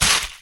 collision.wav